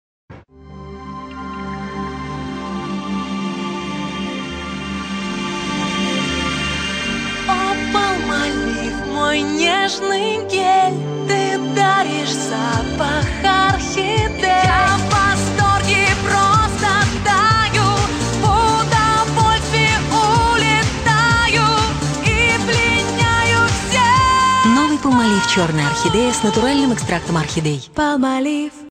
• Качество: 320, Stereo
поп
забавные
женский голос
из рекламы